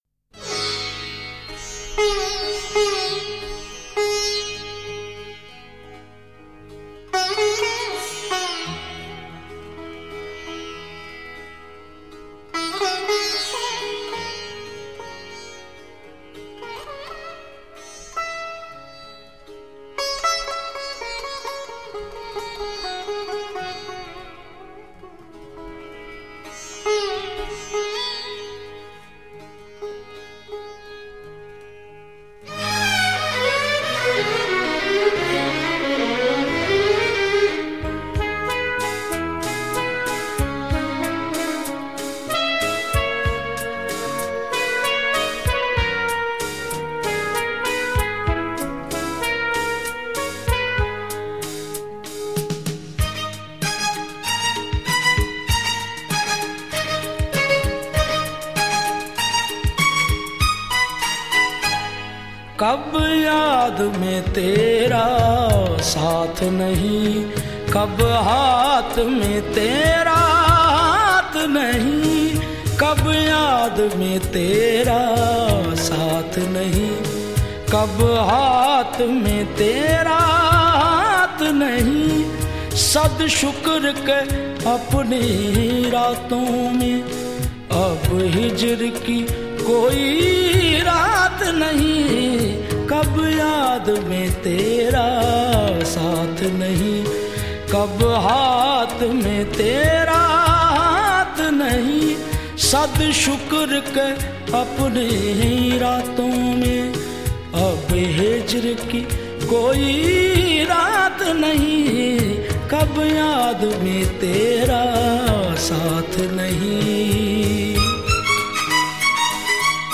a traditional style of qawwali